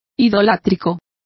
Complete with pronunciation of the translation of idolatrous.